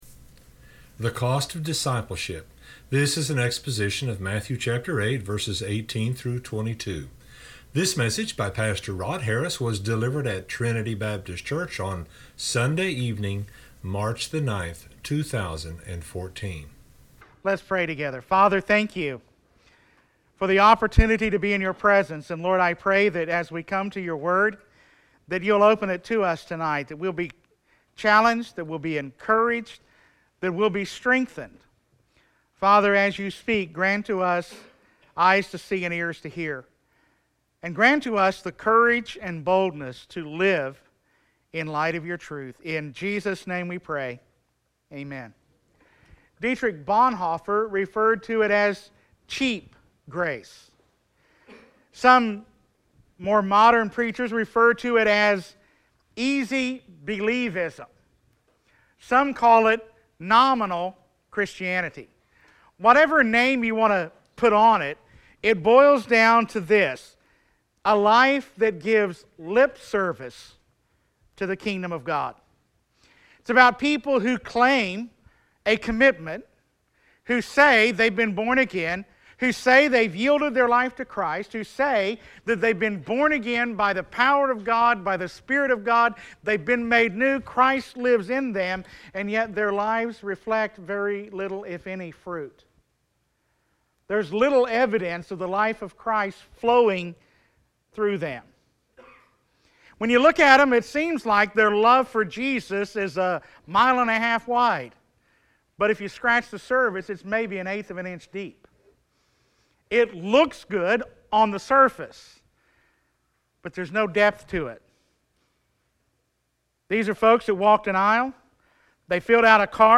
This exposition of Matthew 8:18-22